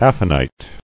[aph·a·nite]